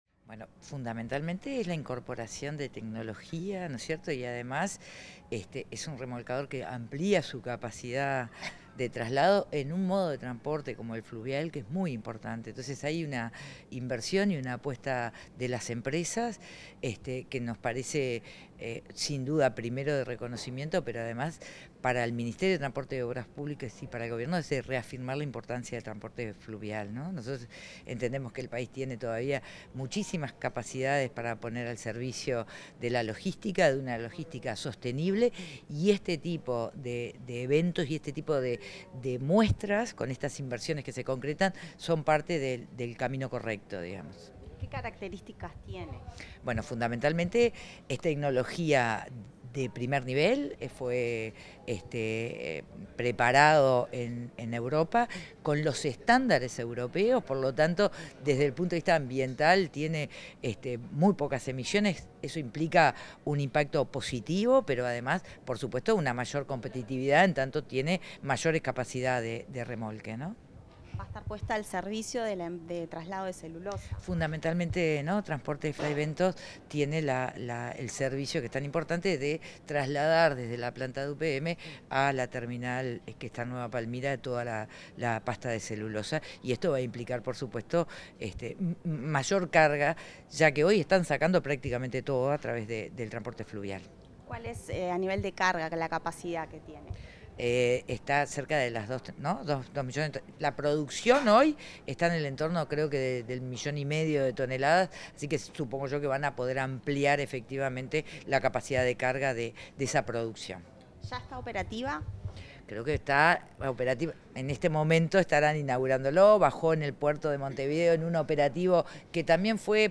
La ministra de Transporte y Obras Públicas, Lucía Etcheverry, dialogó con Comunicación Presidencial, en ocasión de la inauguración del remolcador TFF